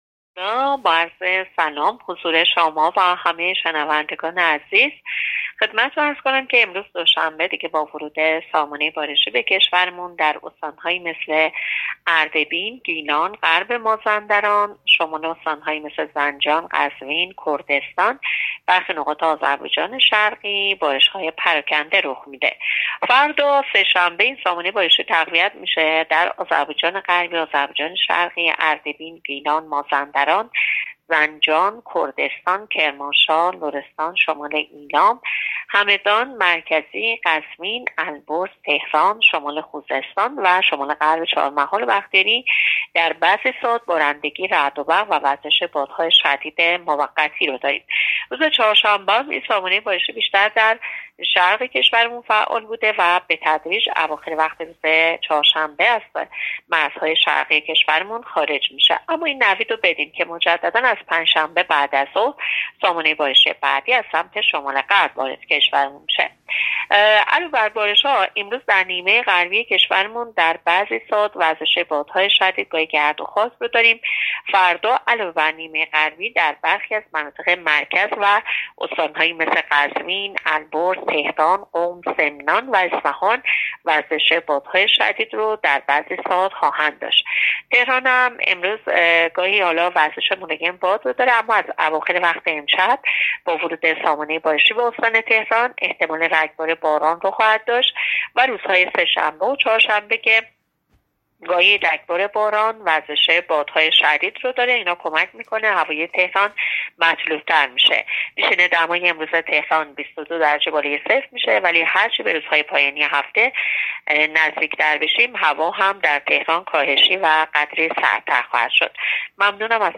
گزارش رادیو اینترنتی از آخرین وضعیت آب و هوای چهارم اسفند؛